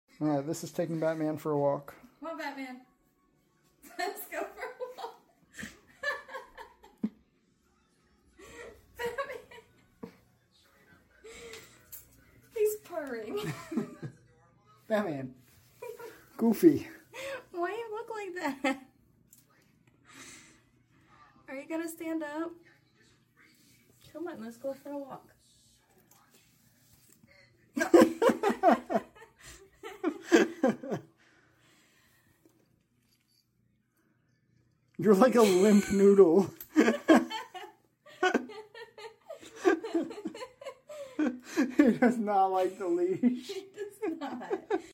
Batman was purring the entire time. This was the reaction we got to putting his harness on him for the first time.